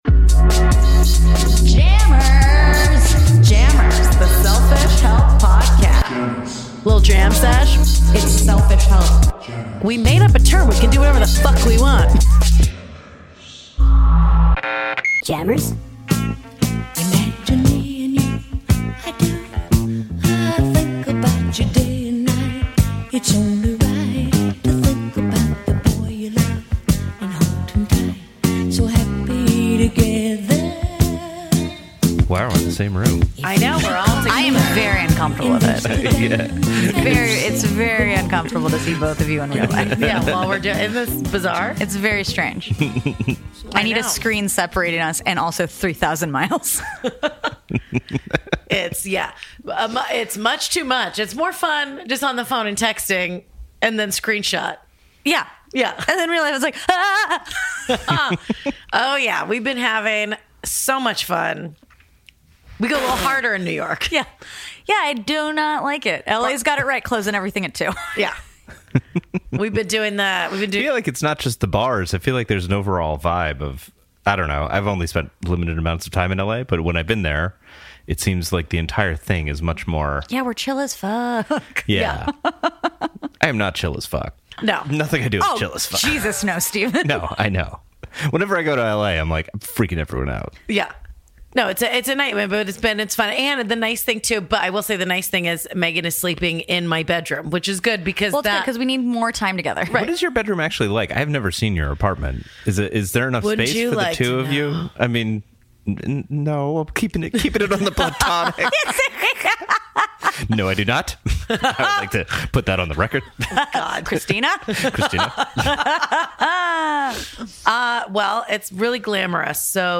That’s right, it’s the SECOND Jammerz adventure recorded outside of the comfort of the studio so all the background sounds you hear are REAL: wind, birds, even the aggressively silent indifference of their Uber driver.